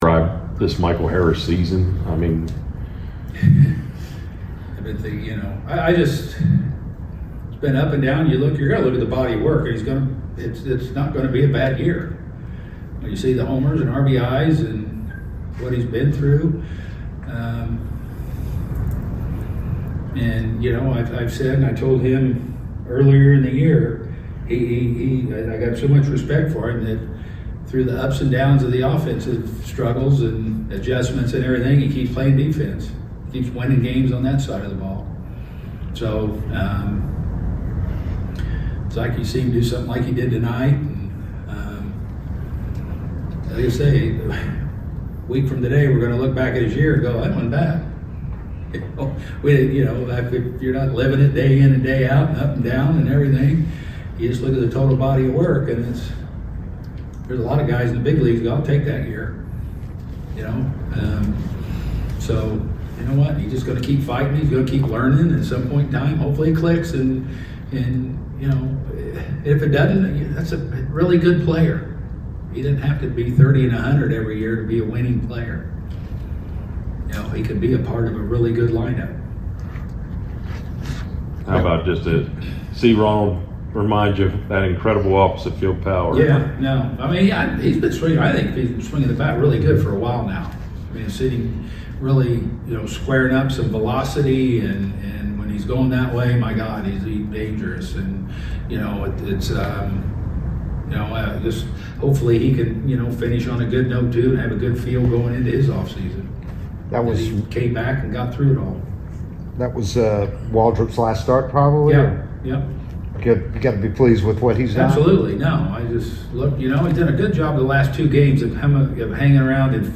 09-23-25 Atlanta Braves Manager Brian Snitker Postgame Interview